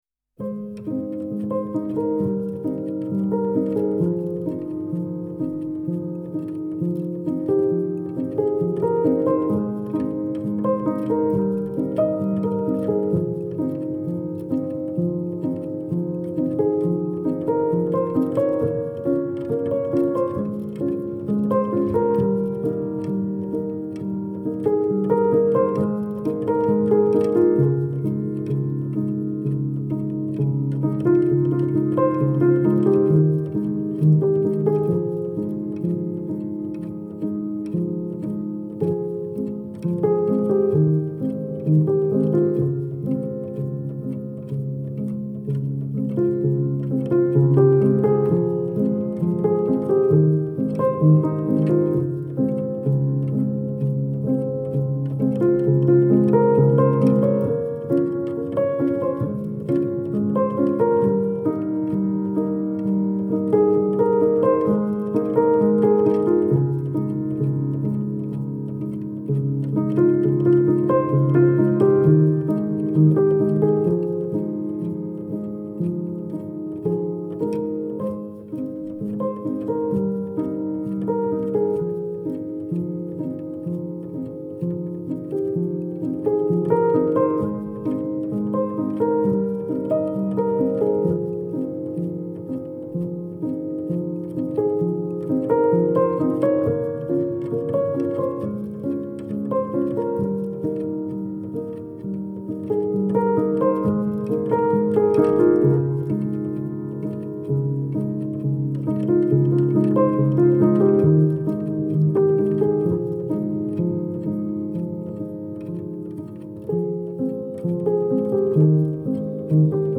Genre : Classical